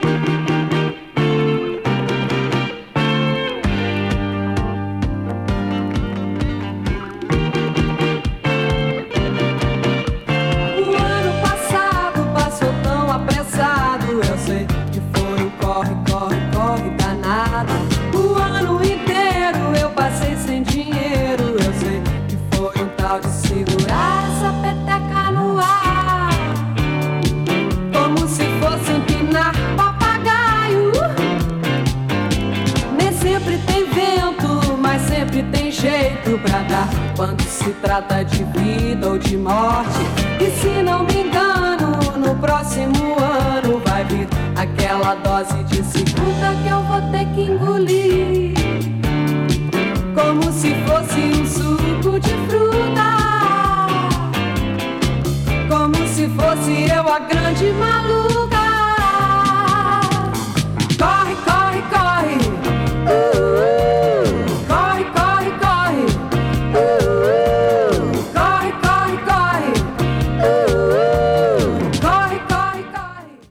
B-5)など、ブラジリアン・ディスコが最高です！